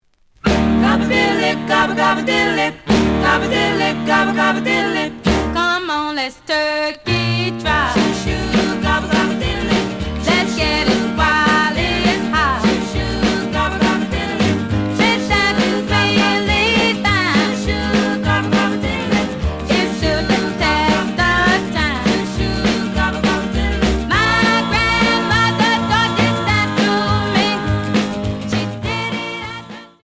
試聴は実際のレコードから録音しています。
●Genre: Rock / Pop
●Record Grading: EX- (盤に若干の歪み。多少の傷はあるが、おおむね良好。)